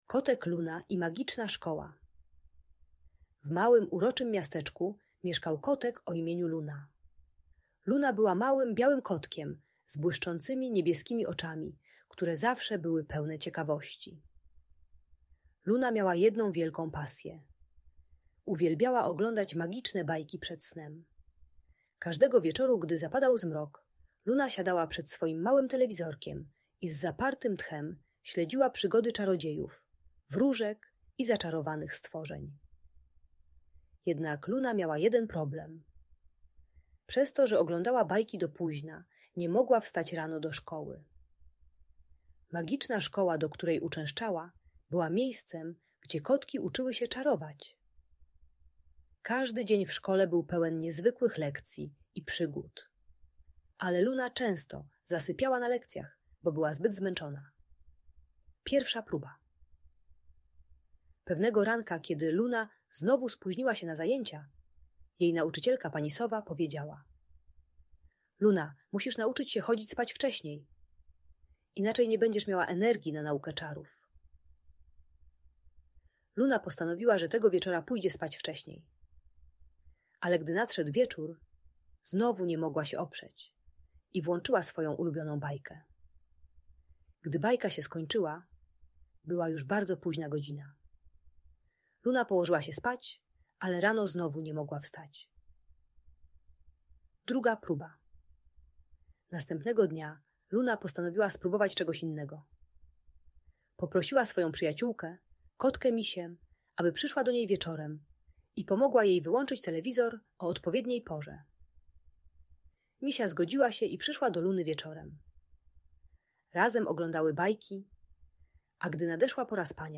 Magiczna historia o kotku Lunie i jej szkolnych przygodach - Audiobajka